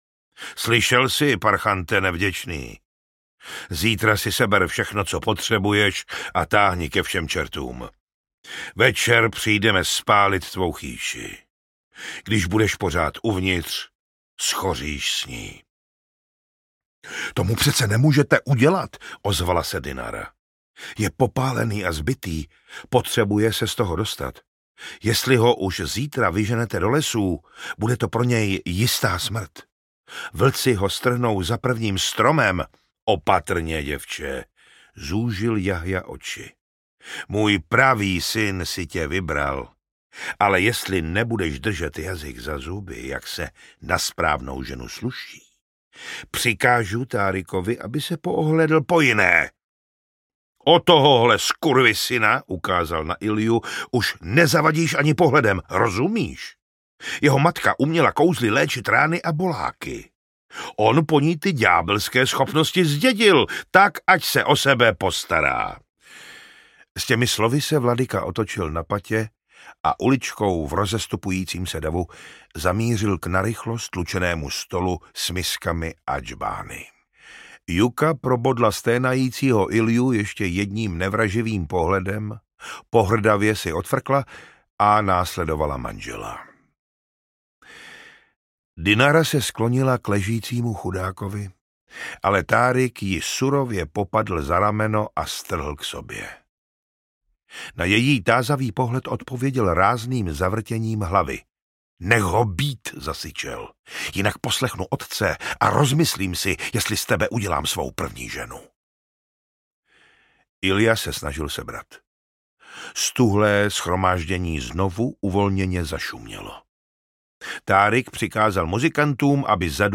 Ocelové žezlo audiokniha
Ukázka z knihy
ocelove-zezlo-audiokniha